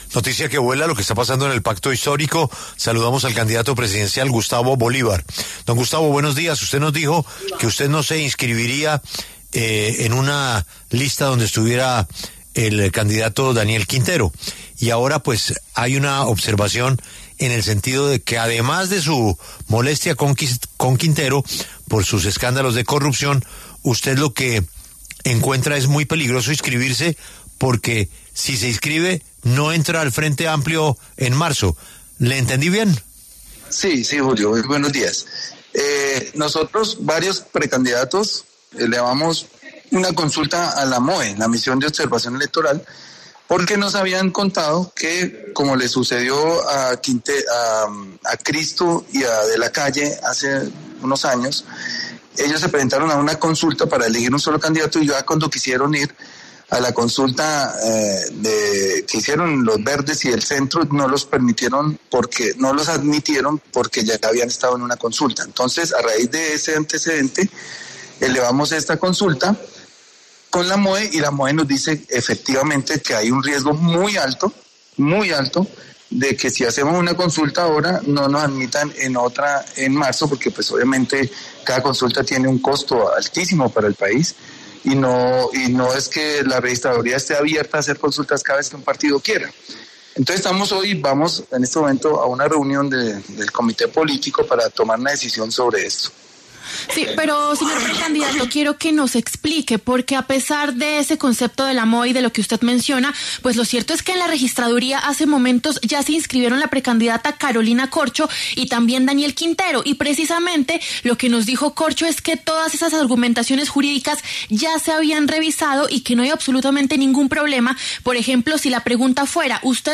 El precandidato presidencial Gustavo Bolívar habló con La W sobre la elección del candidato del Pacto Histórico para las elecciones del 2026.